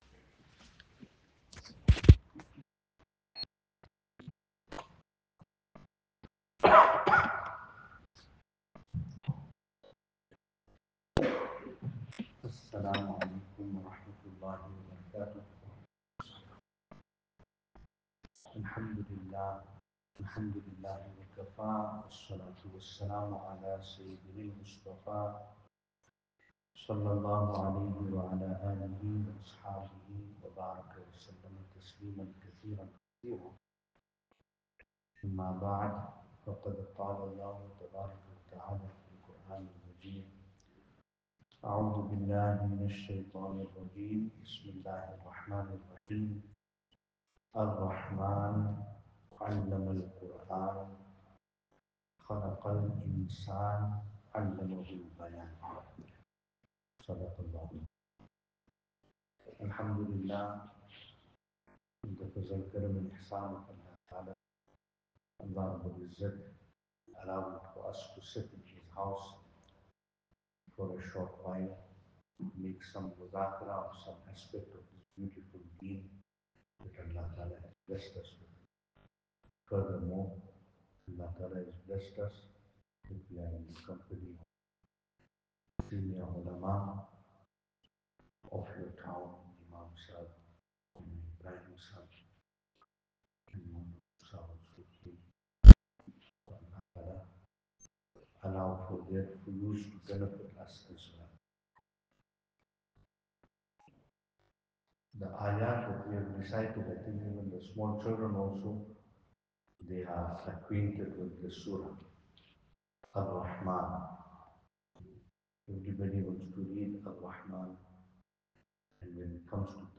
Venue: Musjid Khalid Bin Waleed , Nigel